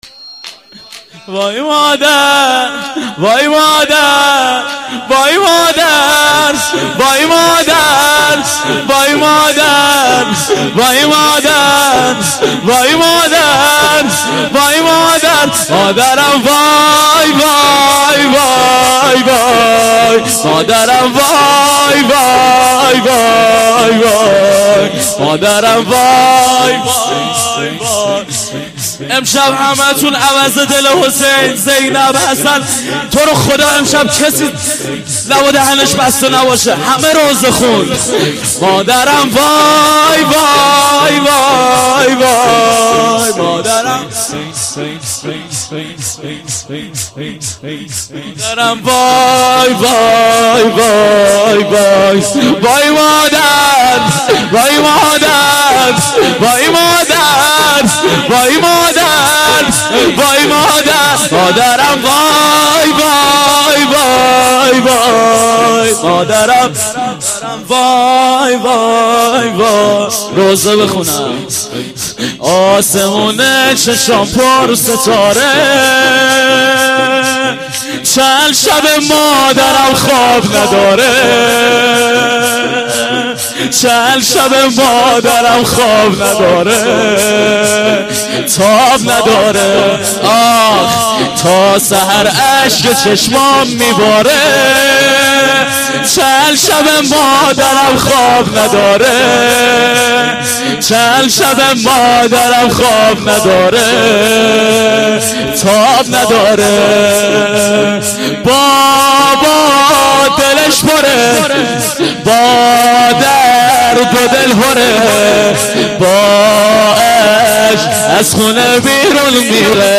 شور - وای مادر